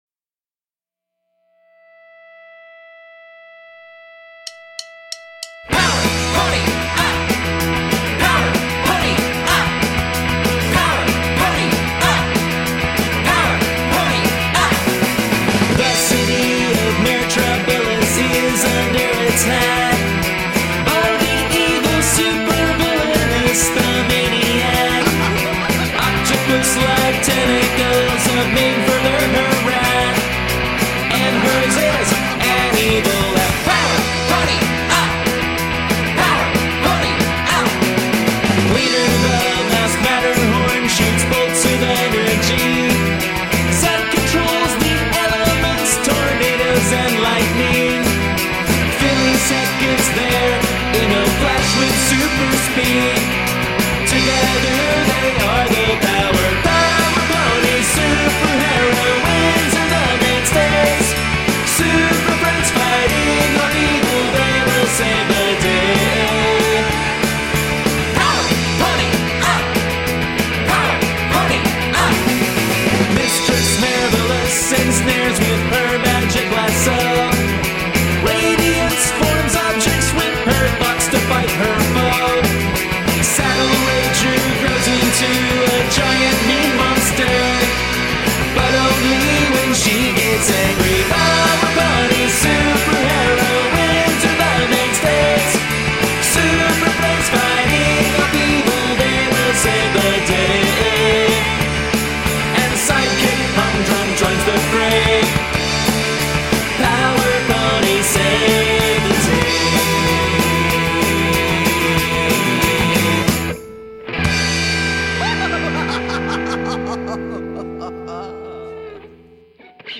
power-pop band